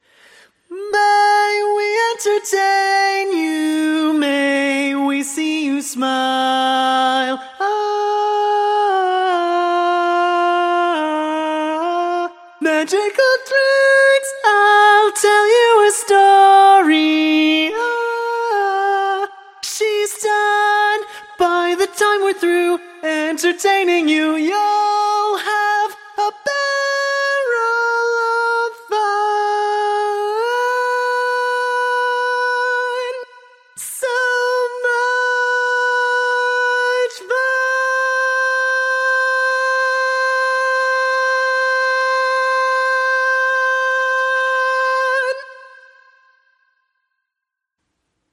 Key written in: A♭ Major
Type: Female Barbershop (incl. SAI, HI, etc)
Each recording below is single part only.